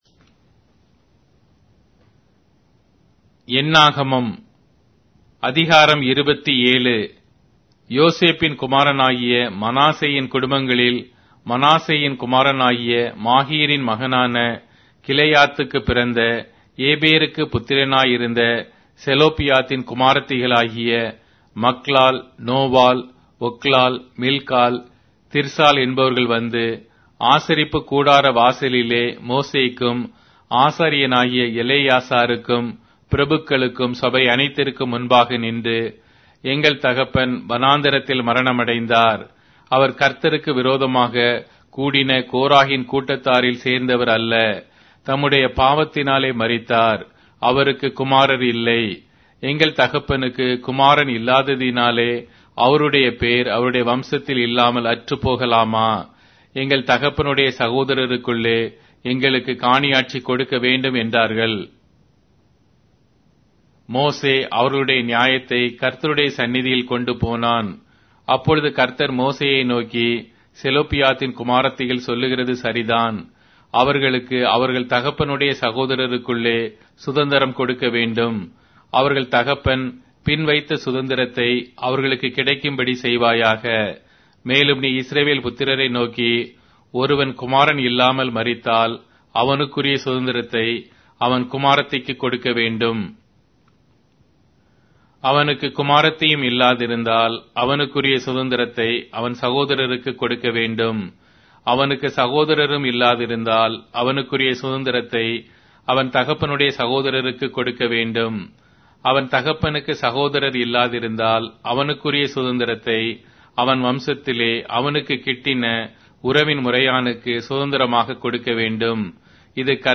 Tamil Audio Bible - Numbers 8 in Ervte bible version